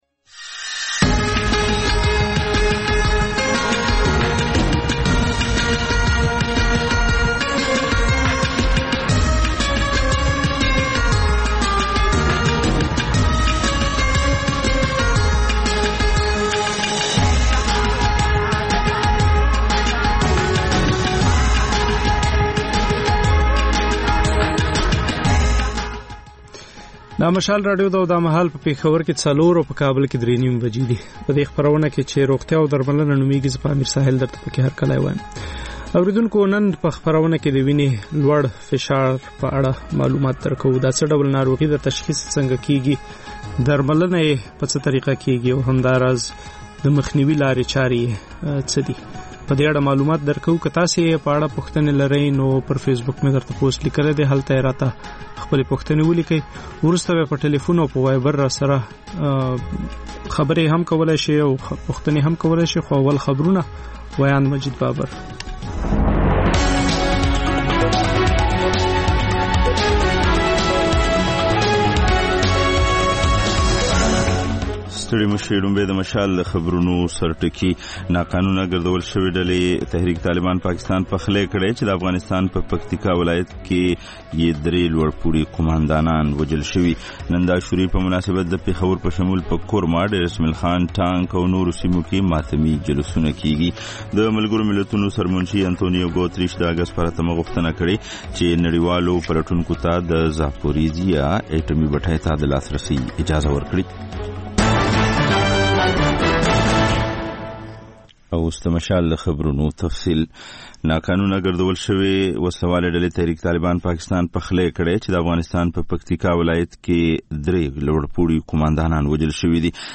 د مشال راډیو مازیګرنۍ خپرونه. د خپرونې پیل له خبرونو کېږي. د دوشنبې یا د ګل پر ورځ د روغتیا په اړه ژوندۍ خپرونه روغتیا او درملنه خپرېږي چې په کې یو ډاکتر د یوې ځانګړې ناروغۍ په اړه د خلکو پوښتنو ته د ټیلي فون له لارې ځواب وايي.